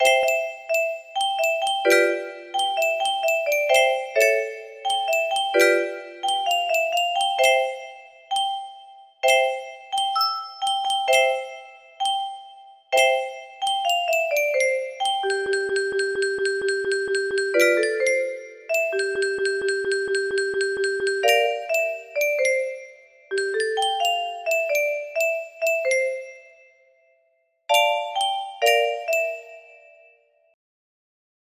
Unknown Artist - Untitled music box melody
Grand Illusions 30 (F scale)
BPM 65